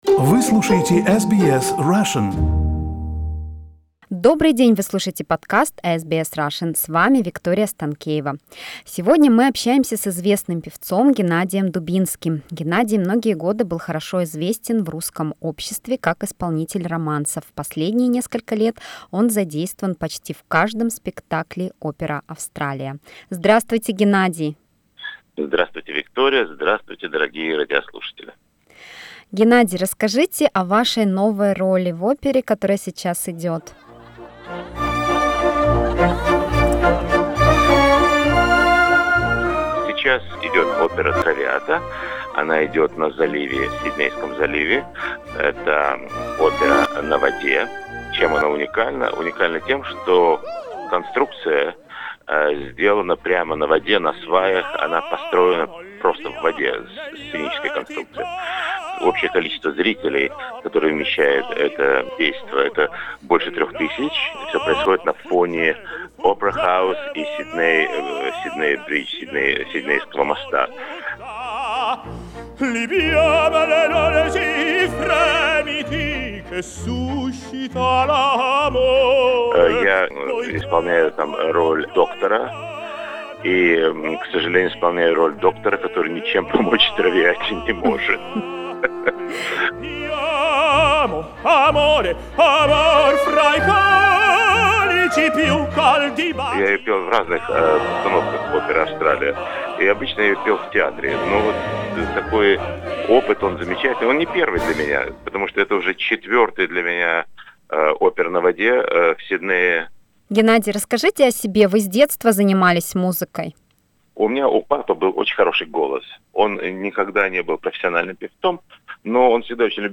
В интервью